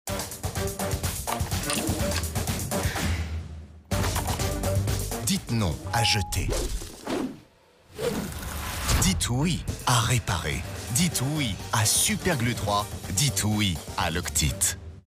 Prestation voix-off pour Loctite Super Glue 3 : ton sympa et naturel
Voix encourageante et souriante.
Pub TV enregistrée chez O’Bahamas et produite par Clever Box Films.
Pour ce faire, j’ai utilisé une tonalité de voix médium grave, qui est à la fois chaleureuse et rassurante. Mon ton est sympathique, souriant, encourageant, motivant et naturel, pour que chaque amateur de bricolage se sente soutenu et inspiré.